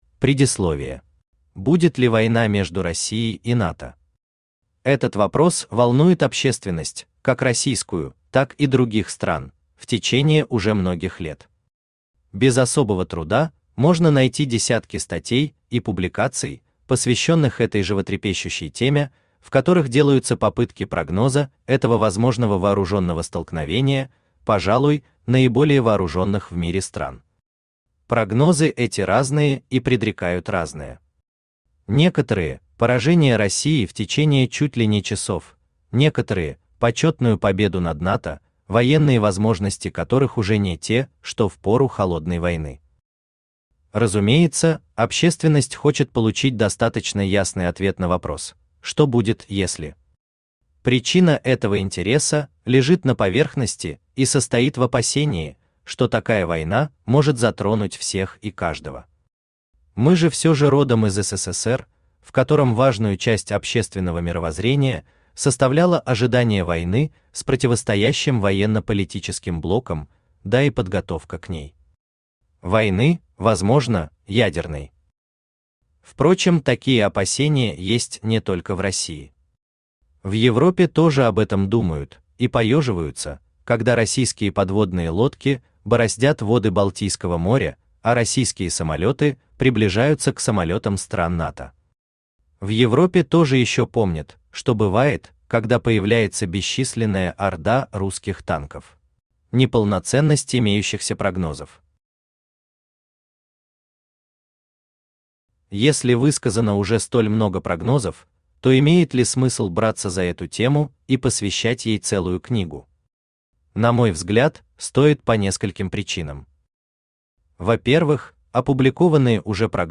Aудиокнига Россия против НАТО: Анализ вероятной войны Автор Дмитрий Николаевич Верхотуров Читает аудиокнигу Авточтец ЛитРес.